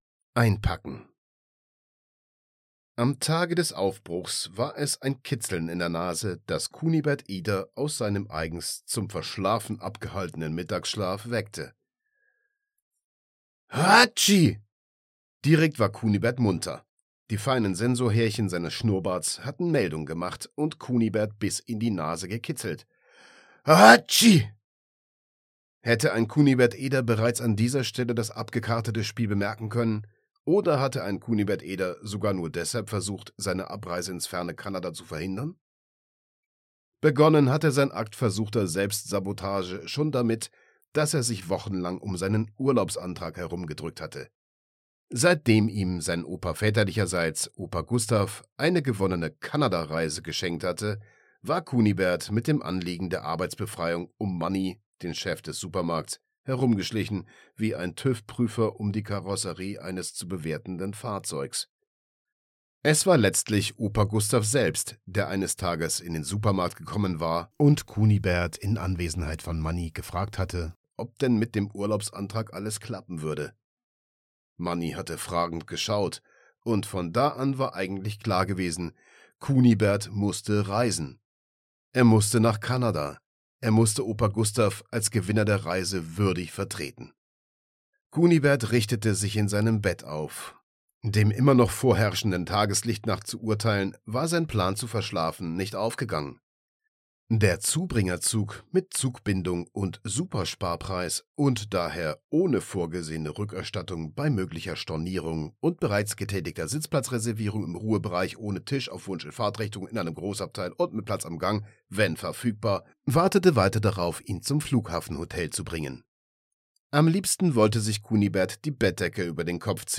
Hoerbuch-Kunibert-Eder-Teil-2-Auf-der-Spur-von-Mr.X-02-Einpacken.mp3